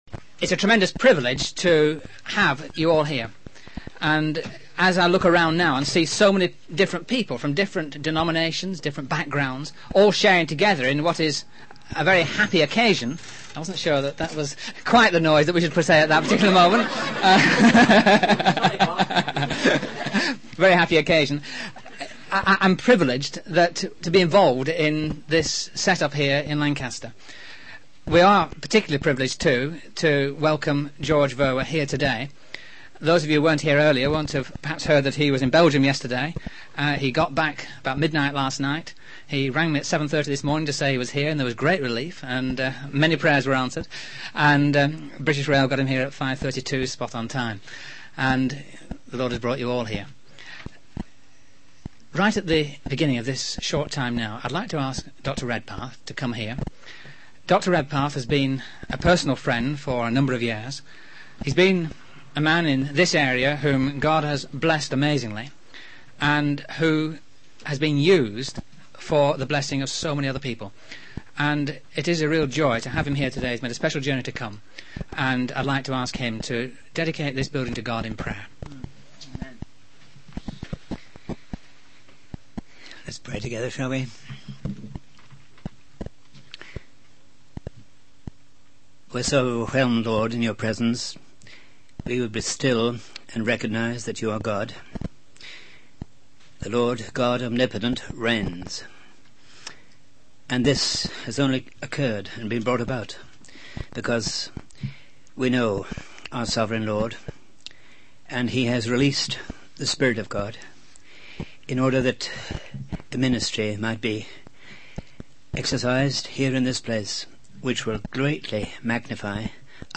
In this sermon, the speaker emphasizes the importance of actively participating in the race of faith rather than being a passive spectator. He encourages the use of literature in ministry, such as distributing books door to door or setting up church book tables. The speaker also dedicates a Christian bookshop to God, praying for its impact in bringing people to know Jesus.